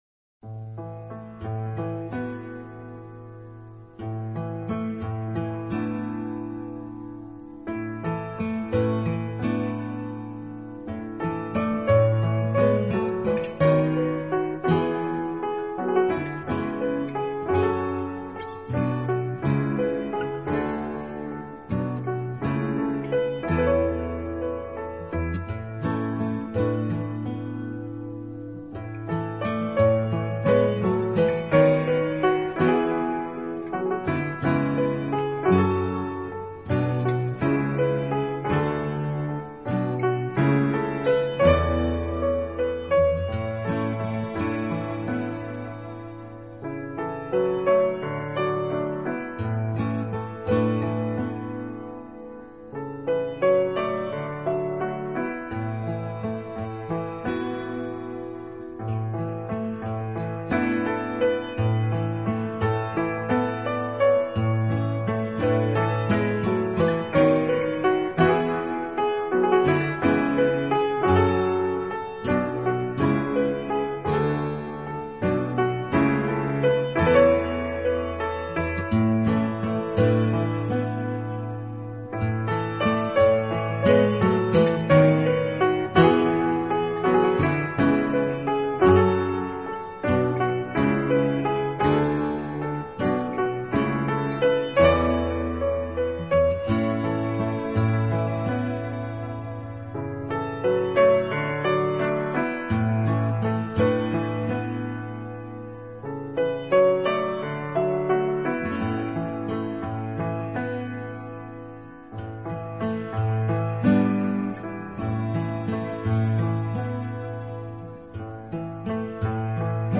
音乐类型：NEW AGE
它有一种难以觉察的简朴的美。
他们的音乐是爵士乐、传统爱尔兰音乐、印象主义室内乐的熔合体，多数由这